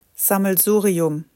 A German word, pronounced [zamlˈzuːriʊm] (